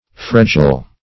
Frejol \Fre"jol\ [Sp. fr['i]jol, fr['e]jol.]